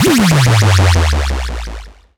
CDK Transition 5.wav